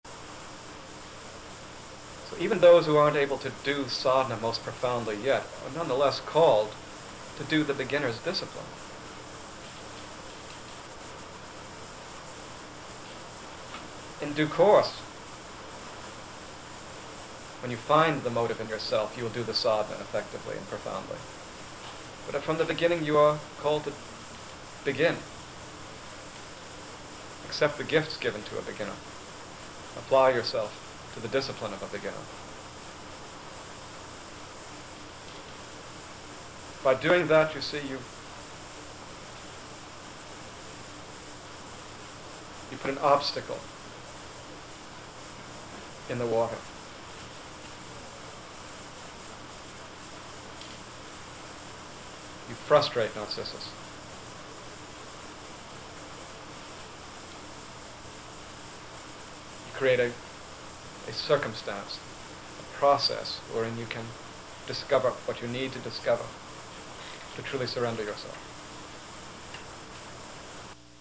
Adi Da talks about the early phase of sadhana: taking on life conditions.